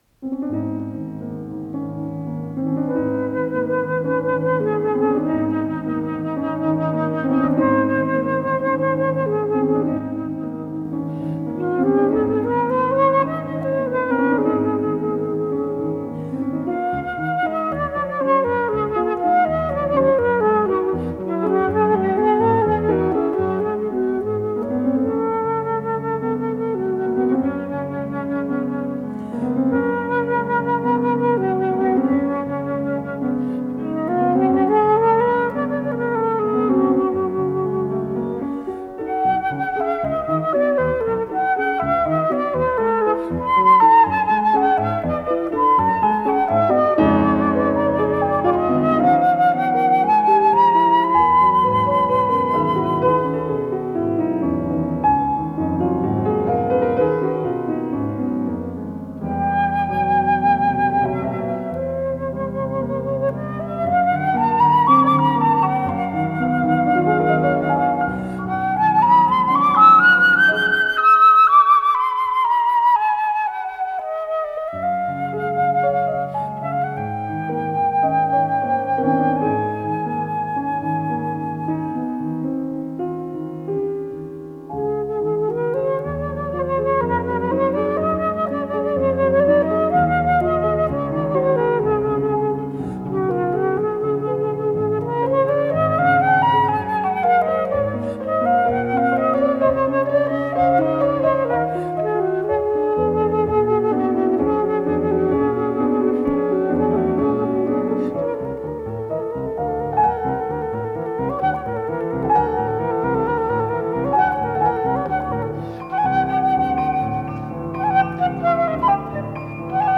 с профессиональной магнитной ленты
флейта
ВариантДубль моно